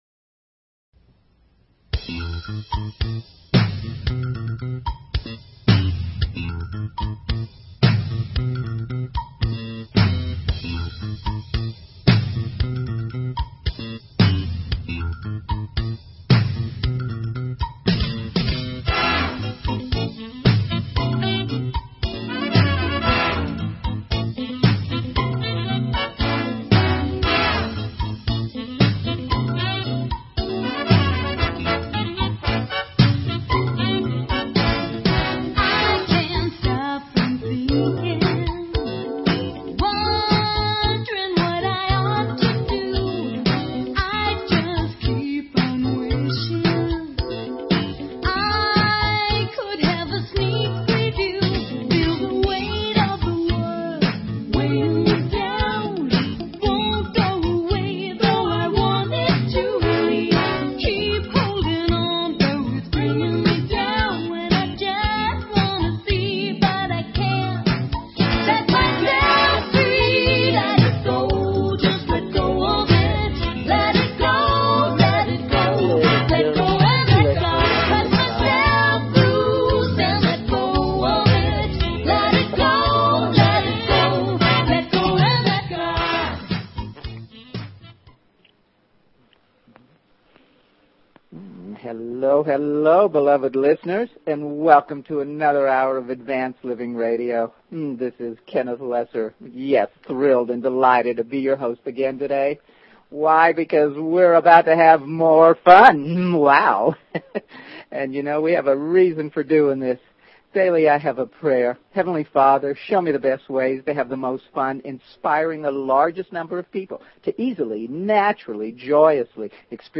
Talk Show Episode, Audio Podcast, Advanced_Living and Courtesy of BBS Radio on , show guests , about , categorized as